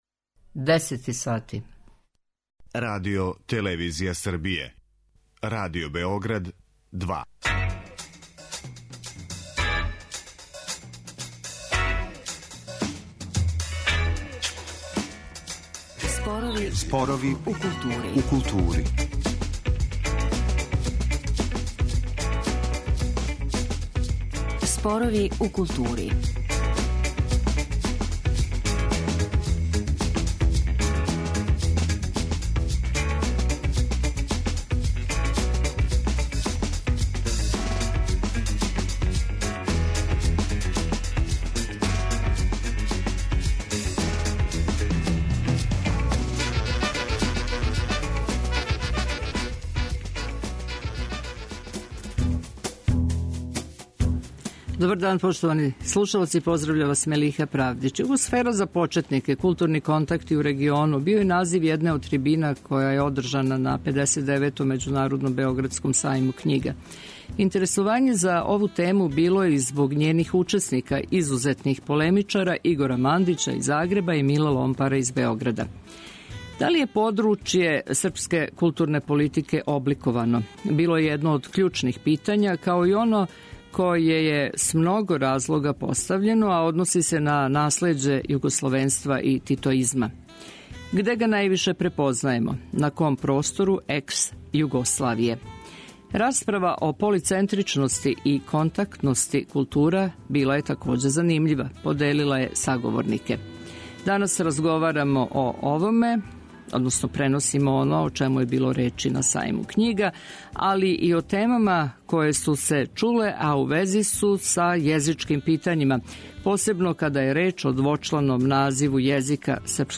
'Југосфера за почетнике, културни контакти у региону' - био је назив једне од трибина која је одржана на 59. Међународном београдском сајму књига.